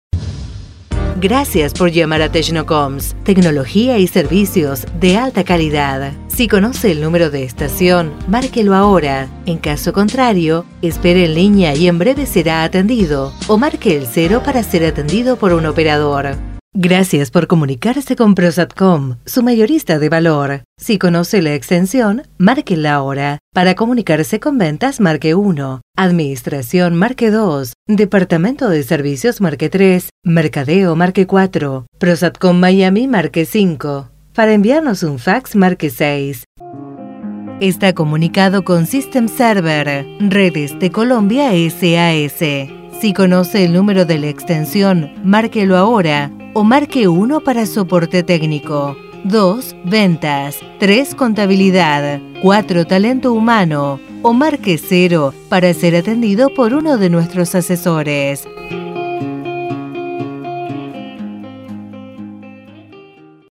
Neutro
El español o castellano neutro es requerido por clientes de centrales telefónicas en diferentes países de Latinoamerica.
neutro.mp3